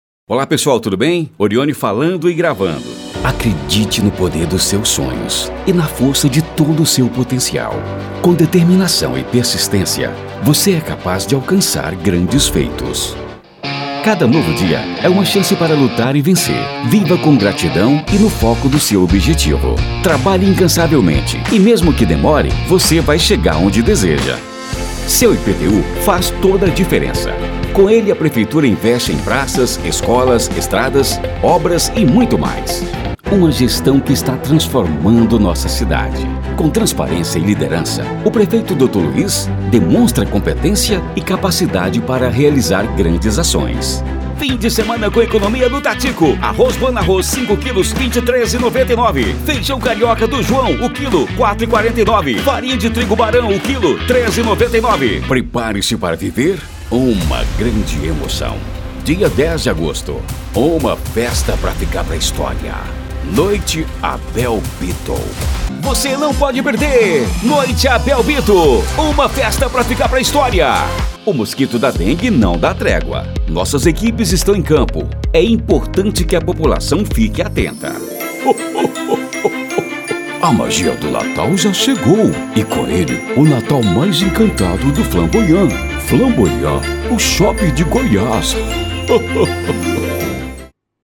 Spot Comercial
Vinhetas
Impacto
Animada